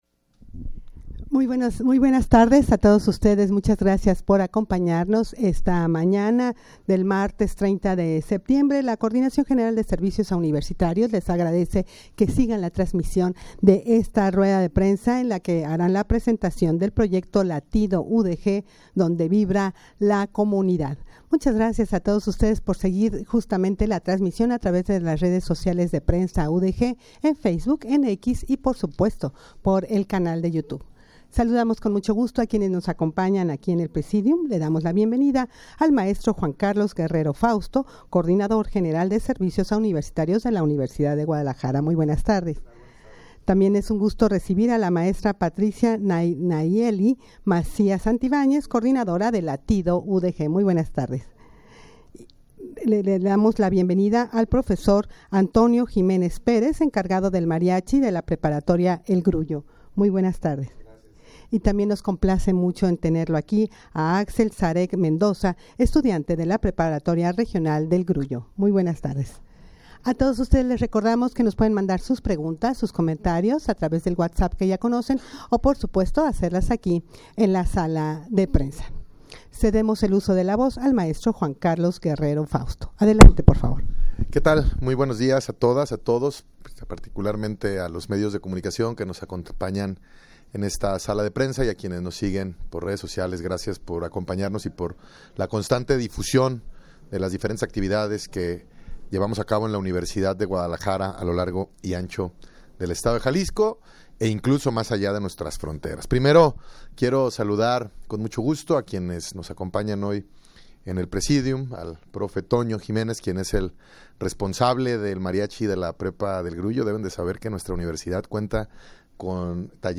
rueda-de-prensa-presentacion-de-proyecto-latido-udg-donde-vibra-la-comunidad.mp3